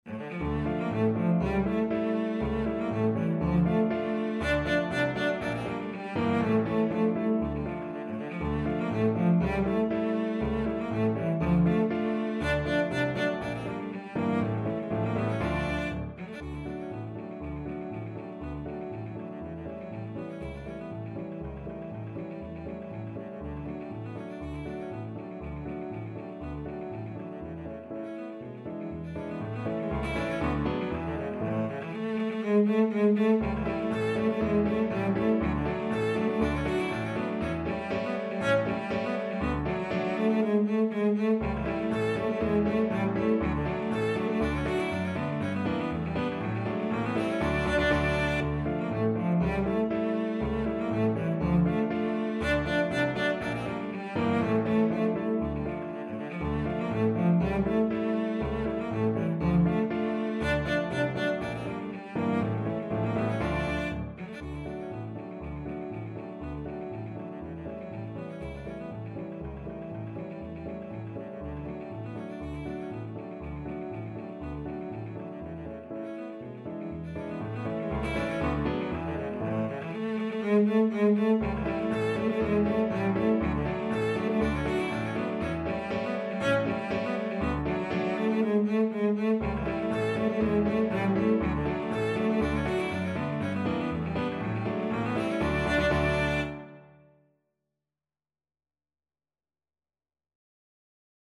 2/4 (View more 2/4 Music)
A3-A5
Classical (View more Classical Cello Music)